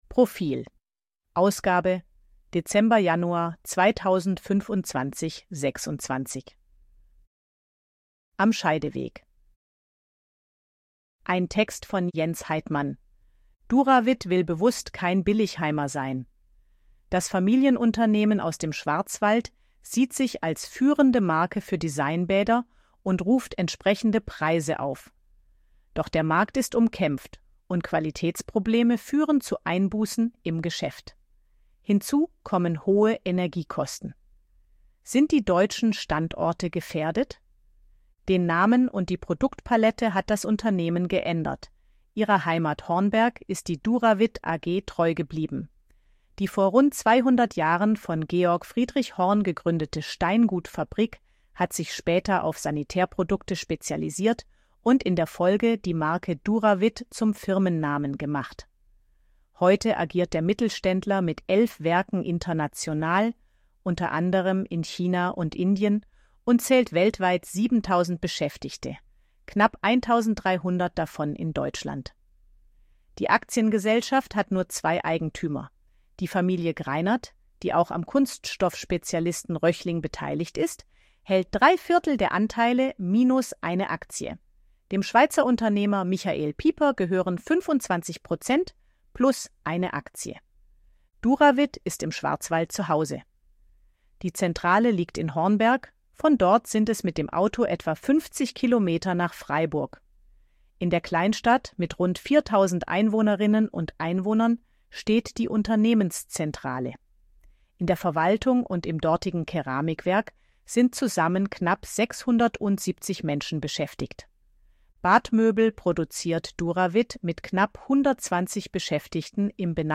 ElevenLabs_256_KI_Stimme_Frau_AG-Check.ogg